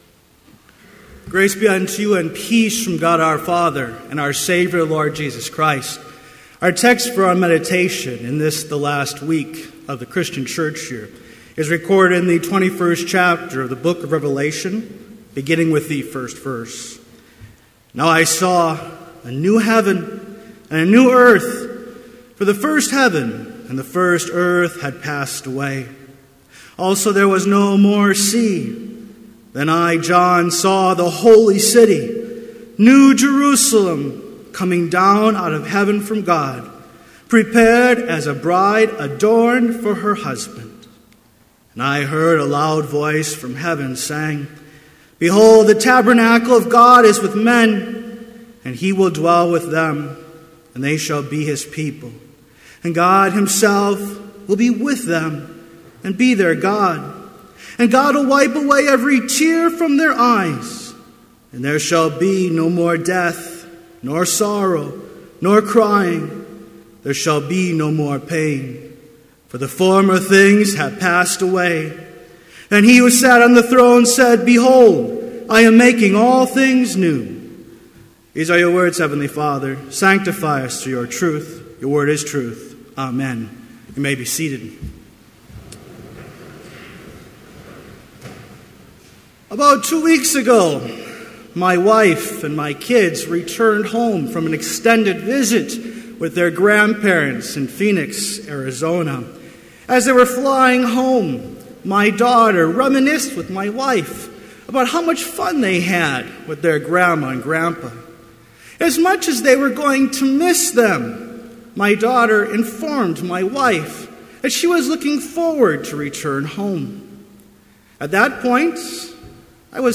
Complete service audio for Chapel - November 25, 2013